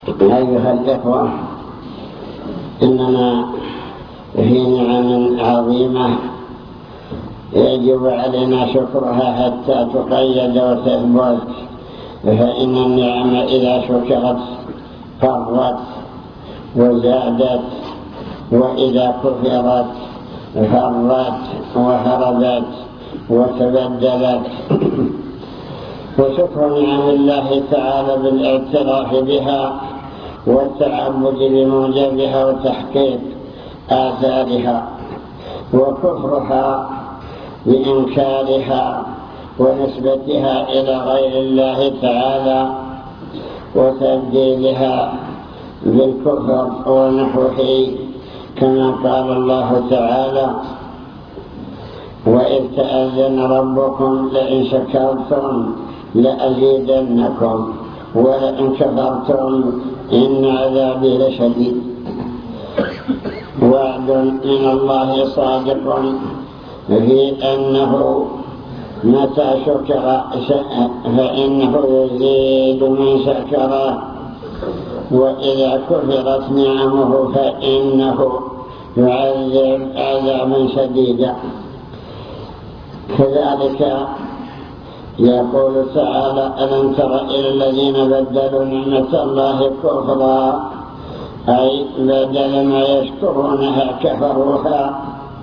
المكتبة الصوتية  تسجيلات - محاضرات ودروس  محاضرة بعنوان شكر النعم (3)